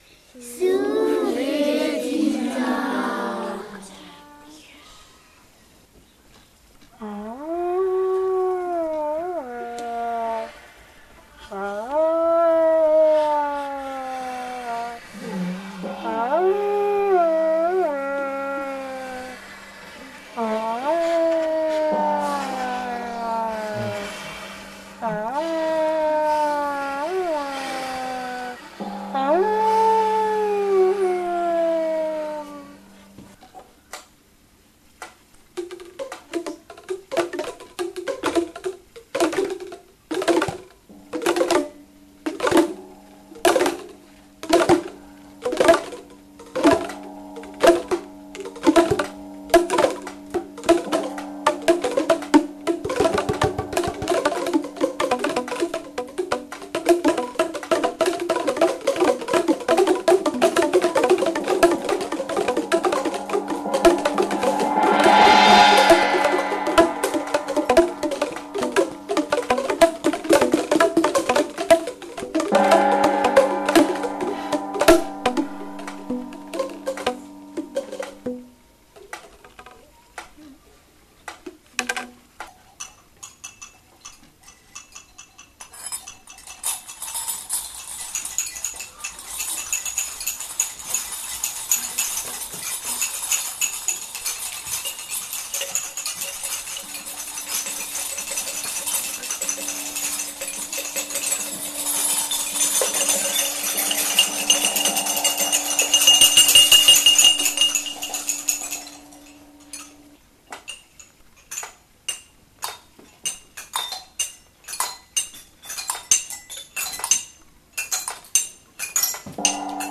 La maison hant�e (souffle et tic tac...)